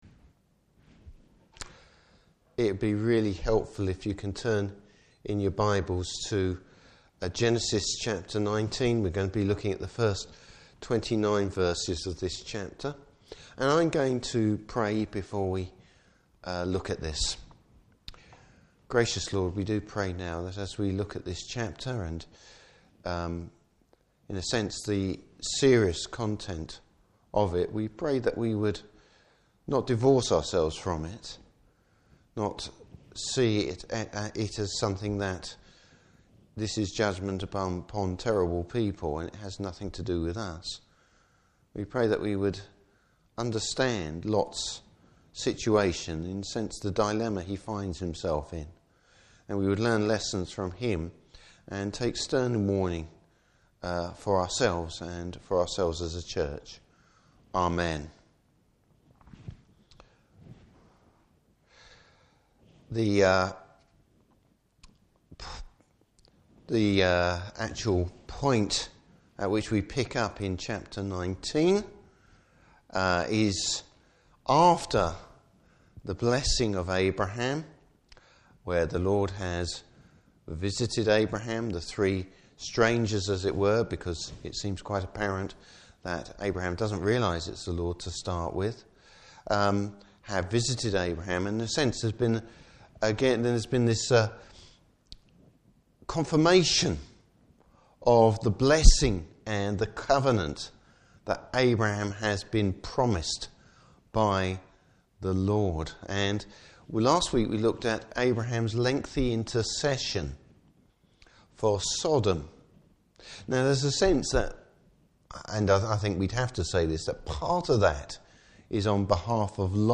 Service Type: Evening Service Lot, the portrait of a compromiser!